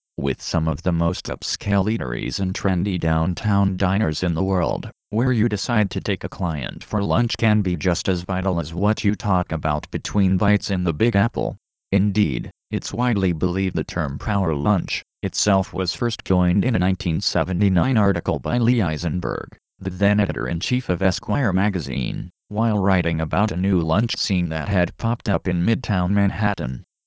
Voice Demo
Cepstral William 16k (U.S. English)